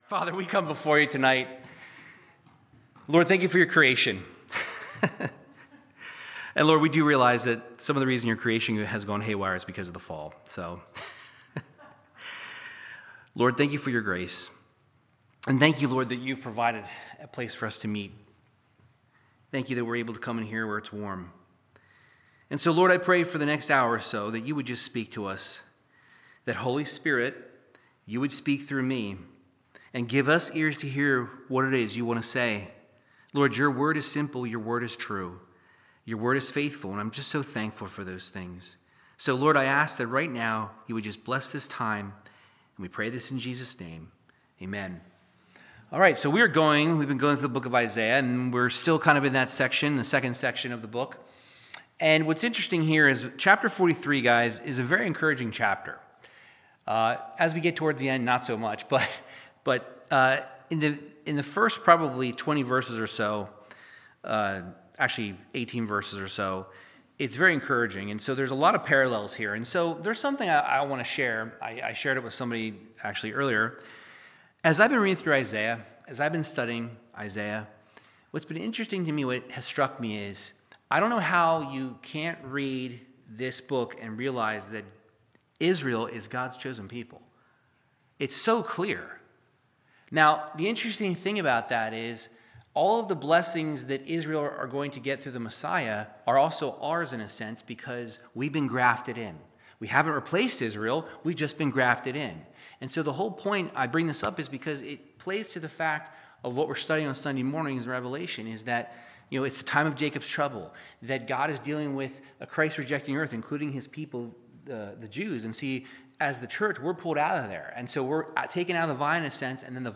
Verse by verse Bible teaching through the book of Isaiah chapter 43